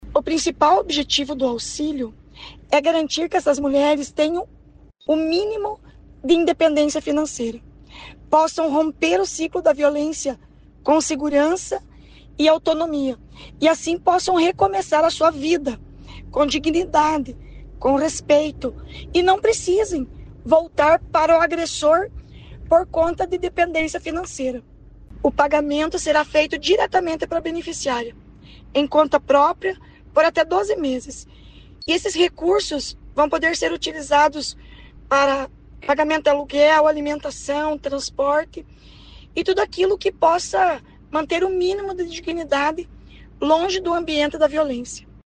Sonora da secretária Estadual da Mulher, Igualdade Racial e Pessoa Idosa, Leandre dal Ponte, sobre o começo do pagamento do Auxílio Social Mulher Paranaense | Governo do Estado do Paraná